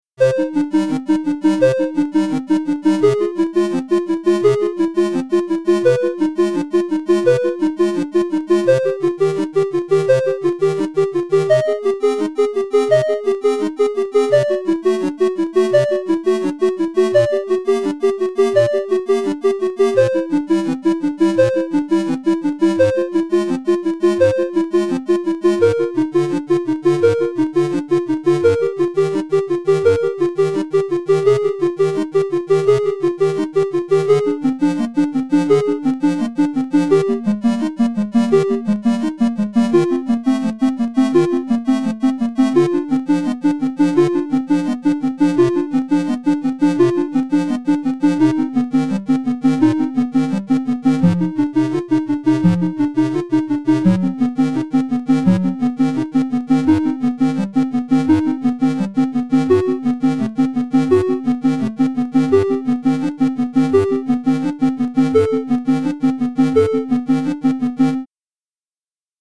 var beatsPerMinute = 85;